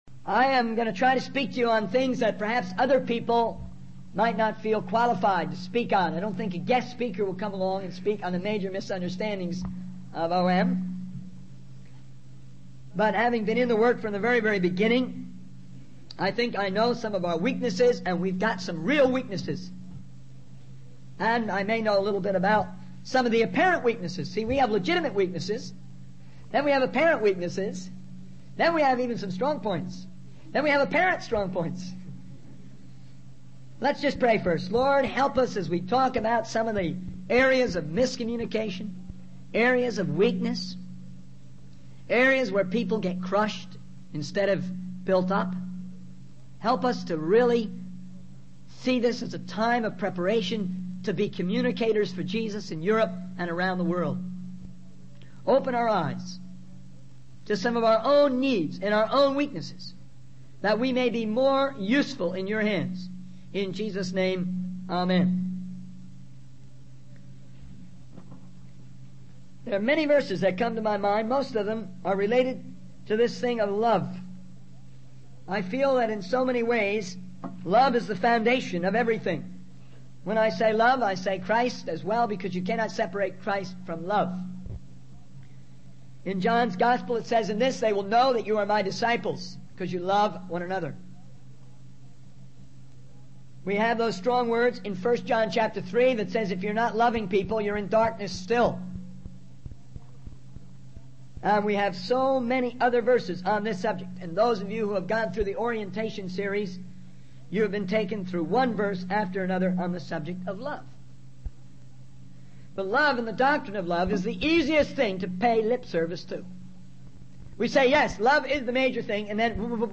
In this sermon, the speaker addresses some common misunderstandings and challenges in the strategy and execution of spreading the word of God. He emphasizes the need for creativity and not relying on having everything laid out in a chart or strategy.